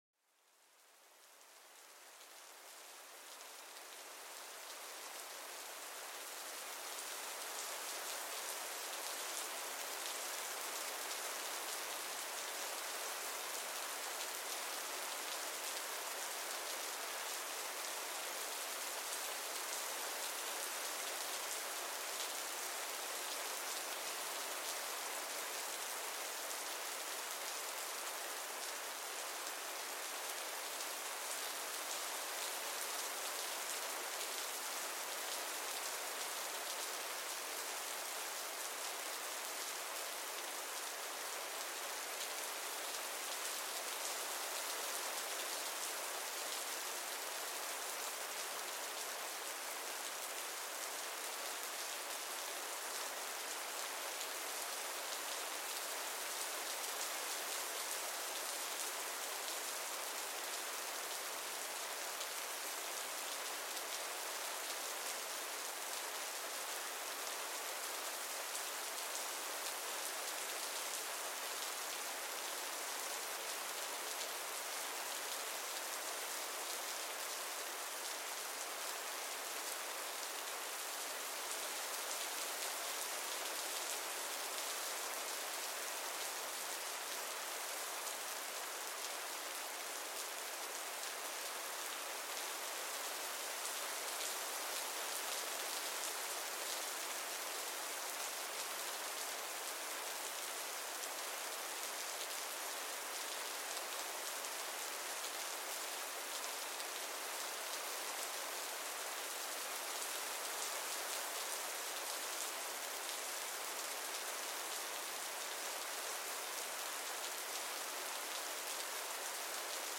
Sumérgete en el sonido relajante de la suave lluvia cayendo lentamente. Cada gota crea una melodía natural que calma la mente y alivia las tensiones. Deja que este ritmo tranquilo te lleve a una relajación profunda y un sueño reparador.Este podcast está dedicado a los sonidos relajantes de la naturaleza, perfectos para calmar la mente y relajar el cuerpo.